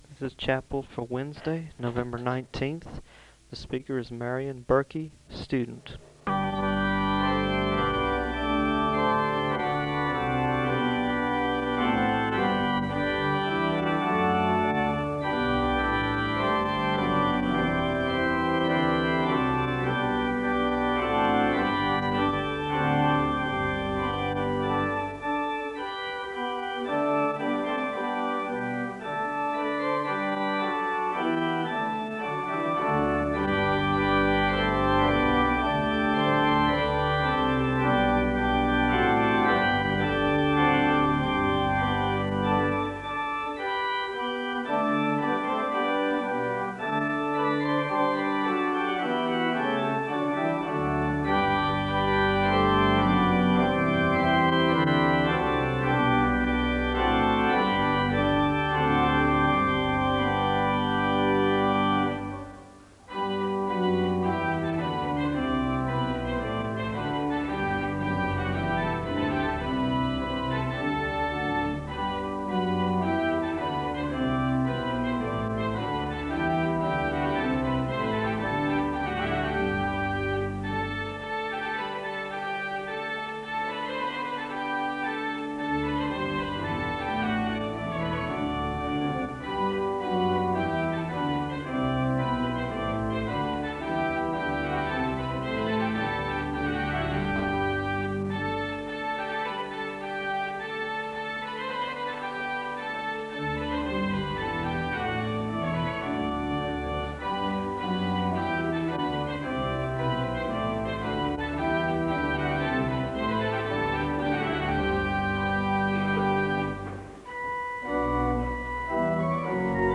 The service begins with organ music (0:00-3:22). There is an announcement for a communion service (3:23-3:36).
There is a Scripture reading and a moment of prayer (3:37-4:37). There are Scripture readings from Deuteronomy 10 and Matthew 5 (4:38-7:38). The choir sings a song of worship (7:39-10:02).
There is a song of worship (25:08-28:42).